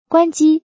power_off.mp3